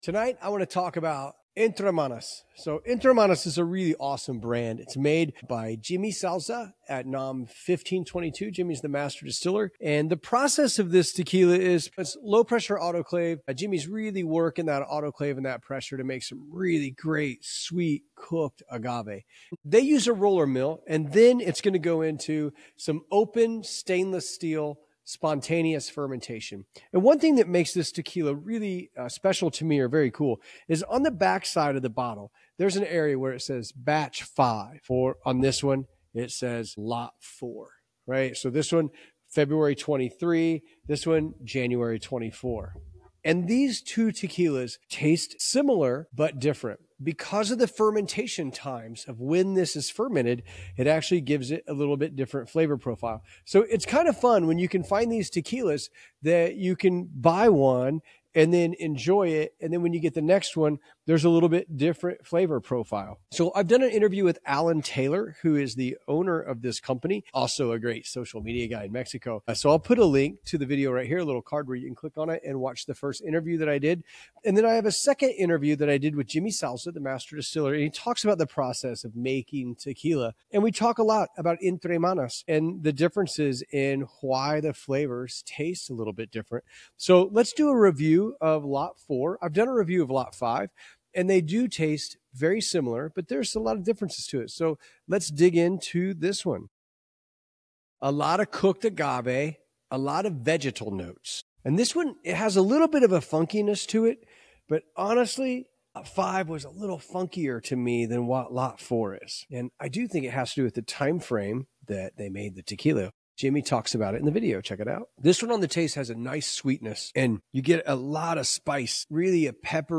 Each episode, we bring you candid conversations with master distillers, brand founders, and agave experts who share their stories, craft secrets, and passion for tequila. Whether you’re a seasoned aficionado or just beginning your tequila journey, join us as we explore the rich culture, traditions, and innovations shaping this iconic spirit.